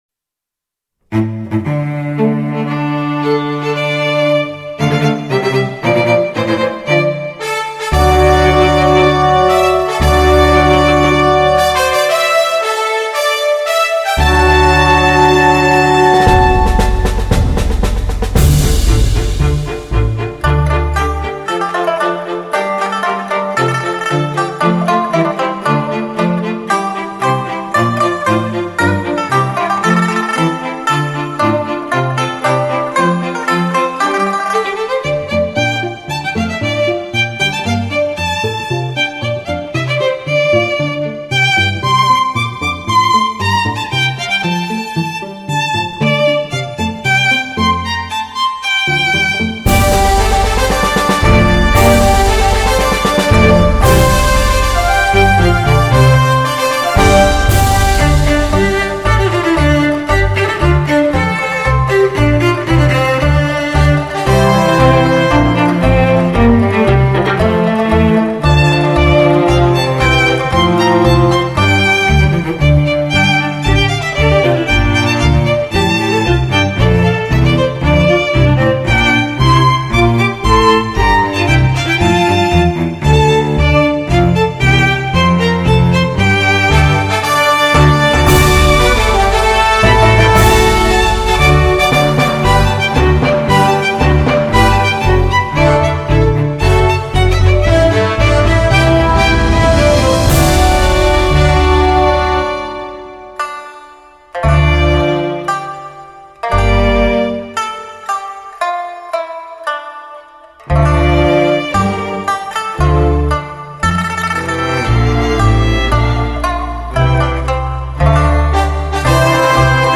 如歌般的旋律:
四重奏如歌般的旋律,如清泉般自然流露,浑然天成.
演奏与作品的高度和谐,无可挑剔的极品四重奏,丰沛的旋律与多彩的变化,完美的录音.